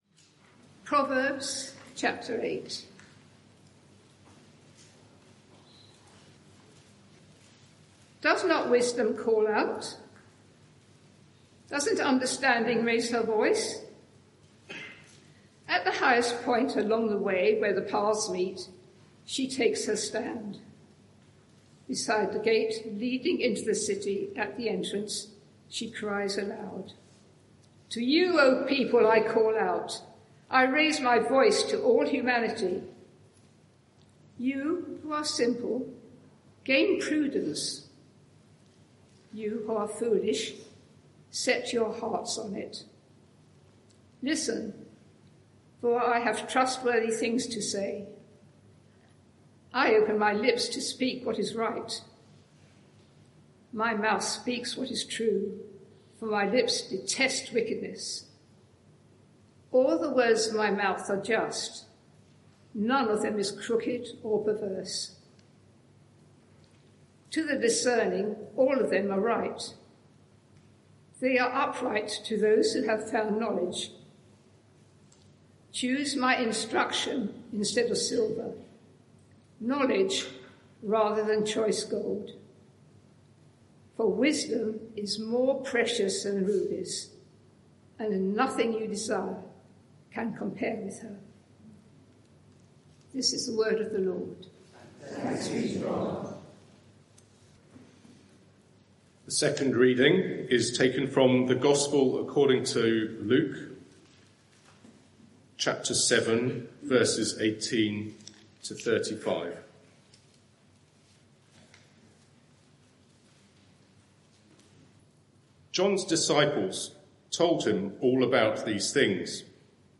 Media for 11am Service on Sun 16th Mar 2025 11:00 Speaker
Passage: Luke 7:18-35 Series: What a Saviour! Theme: Luke 7:18-35 Sermon (audio) Search the media library There are recordings here going back several years.